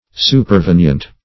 Supervenient \Su`per*ven"ient\, a. [L. superveniens, p. pr.]